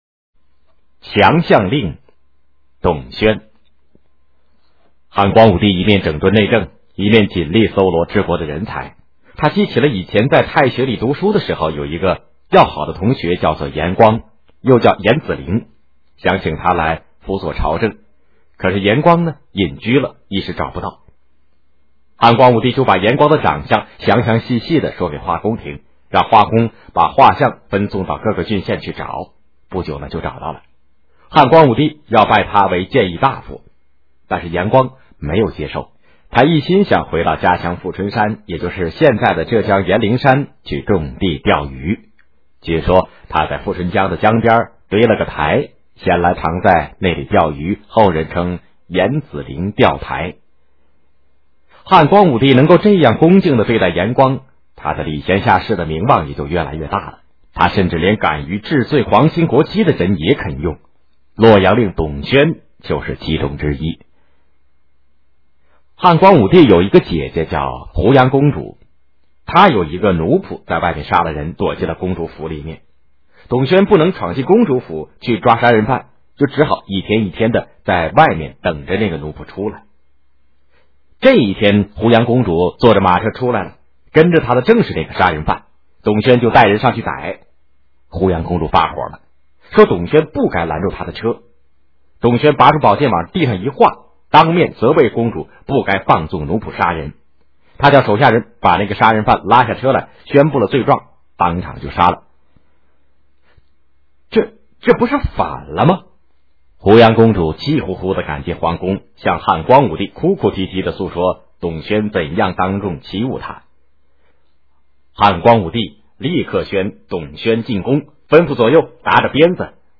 《强项令》原文和译文（含mp3朗读）